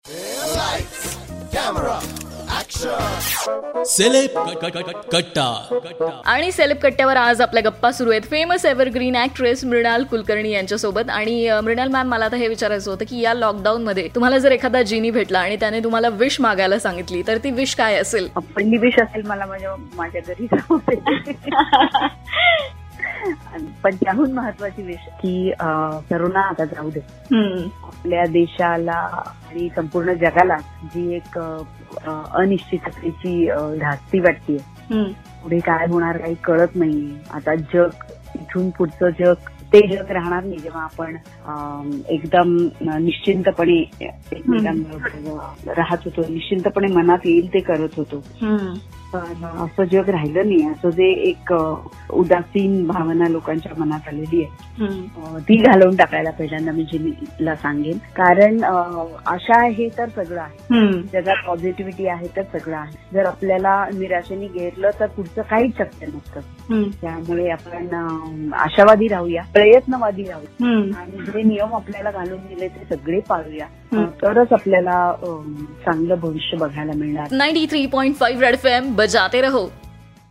took an interview of famous actress Mrinal Kulkarni..In this interview she shared what 3 wishes she wants to fulfilled..